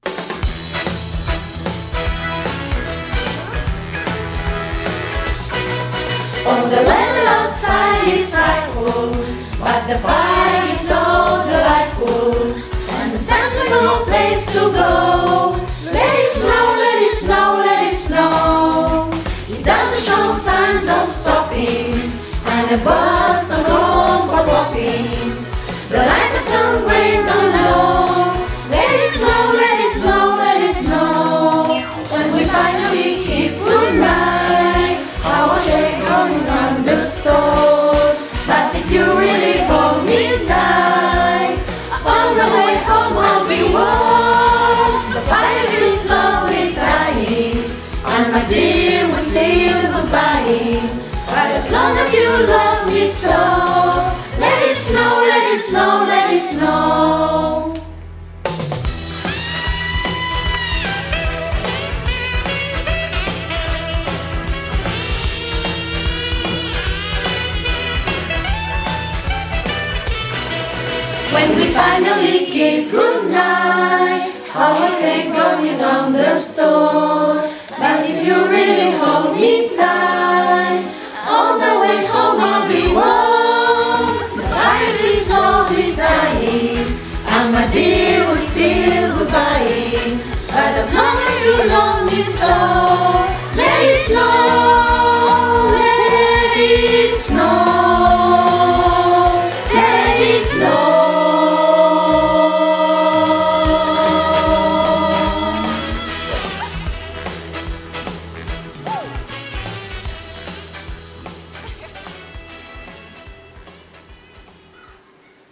I els mestres també vam voler cantar la cançó en anglès “LET IT SNOW”, aquí la teniu.